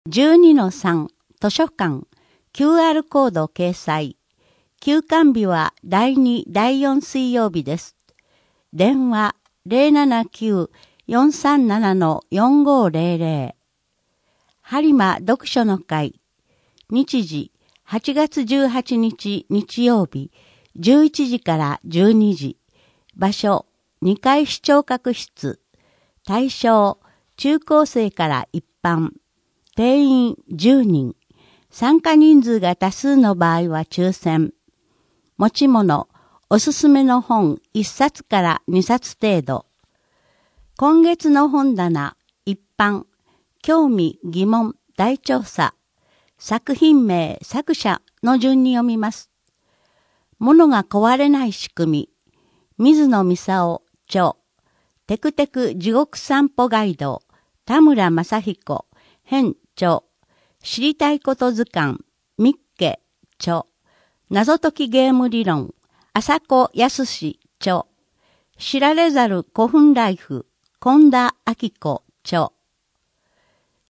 声の「広報はりま」8月号
声の「広報はりま」はボランティアグループ「のぎく」のご協力により作成されています。